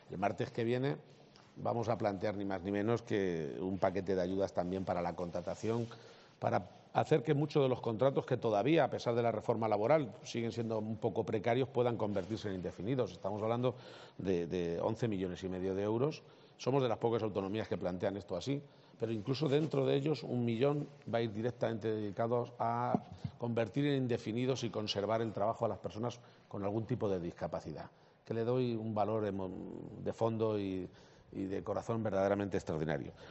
>> Así lo ha anunciado el presidente de Castilla-La Mancha en la inauguración de la XLI edición de la Feria Regional de Artesanía